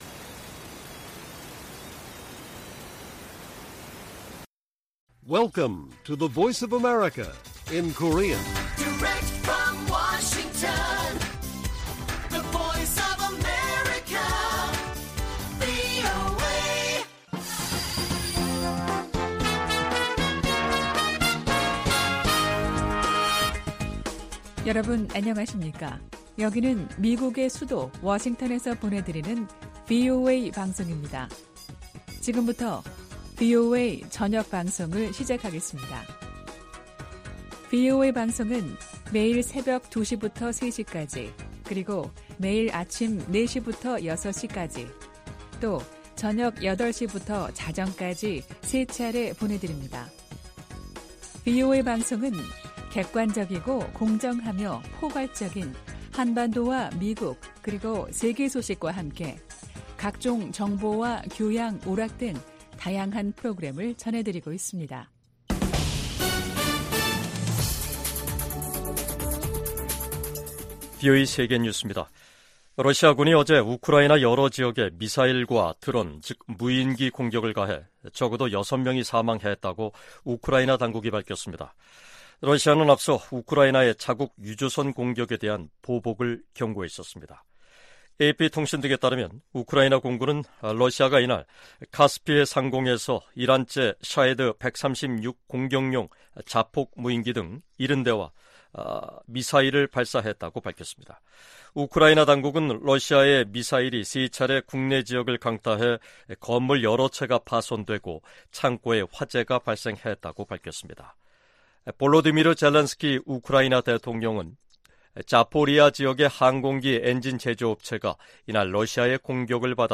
VOA 한국어 간판 뉴스 프로그램 '뉴스 투데이', 2023년 8월 7일 1부 방송입니다. 유엔 제재 대상 북한 유조선이 중국 해역에서 발견된 가운데 국무부는 모든 제재 위반을 심각하게 받아들인다고 밝혔습니다. 미국 민주당 상원의원들이 바이든 행정부에 서한을 보내 북한의 암호화폐 탈취에 대응하기 위한 계획을 공개할 것을 요구했습니다.